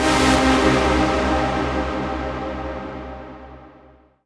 VEC3 FX Athmosphere 07.wav